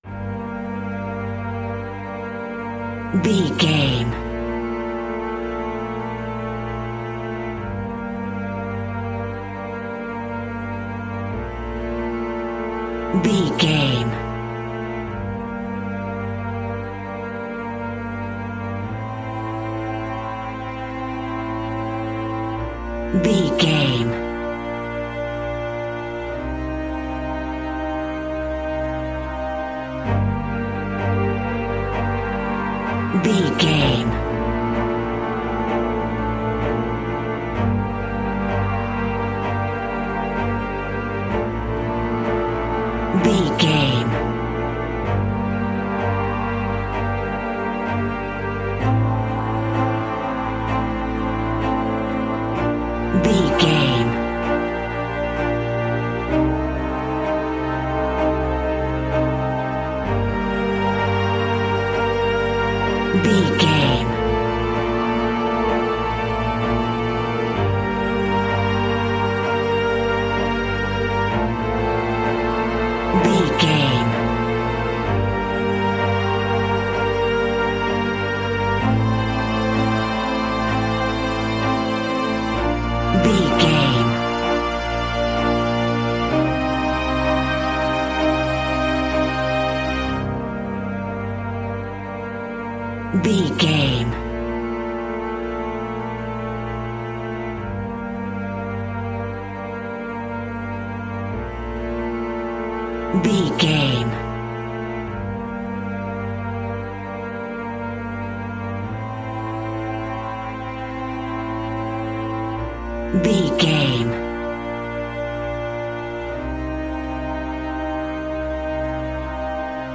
Aeolian/Minor
dramatic
epic
powerful
percussion
synthesiser
brass
violin
cello
double bass